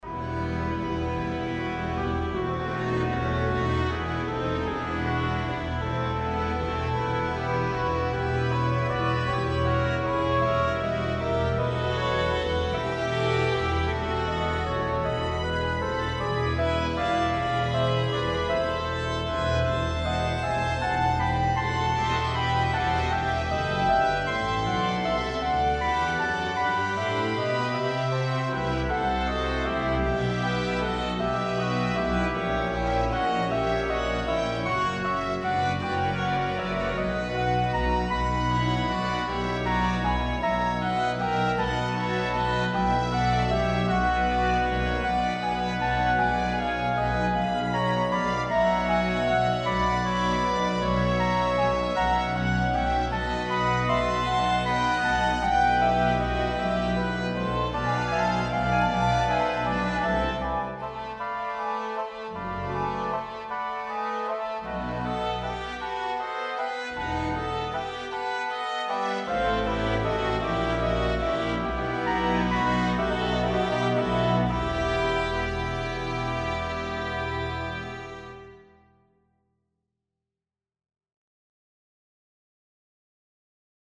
Here’s what I’ve done with Miroire. Again, while there are some balance issues (no dynamics in the score!), the general phrasing is so much better than raw.
For a purpose of a prelimary review of orchestra rehersal, the NPPE 5 one sounds like in a large room not a hall, it is very suitable for this purpose.
The snare drum in NPPE is too dry where the tail sound is cut off, resulting like a MIDI sound.
At 2:55, the NPPE suspended cymbal crescendo is less ideal, like crash cymbal tremolo, while still quite acceptable.
I must say the NPPE audio has been mixed professionally that it does not sound worse than products of any professional orchestral VST with a good reverb.